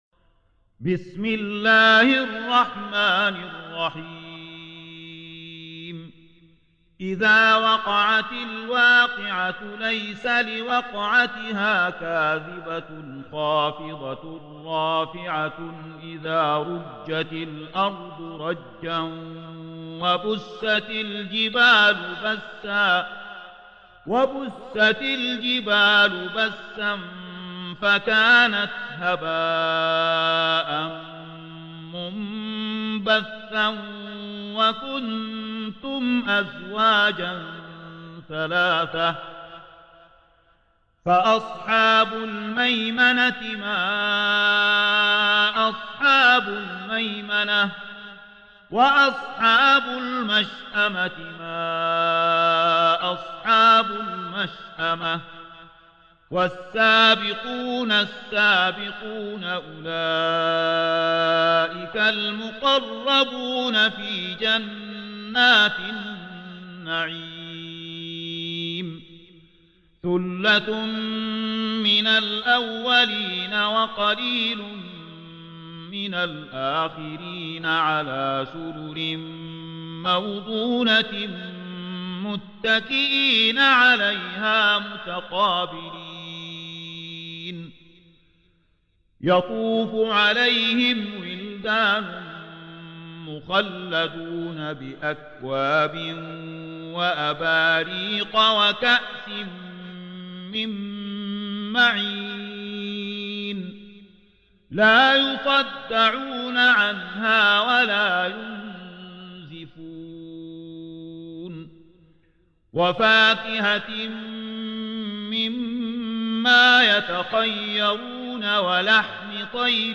Tarteel Recitation
Surah Sequence تتابع السورة Download Surah حمّل السورة Reciting Murattalah Audio for 56. Surah Al-W�qi'ah سورة الواقعة N.B *Surah Includes Al-Basmalah Reciters Sequents تتابع التلاوات Reciters Repeats تكرار التلاوات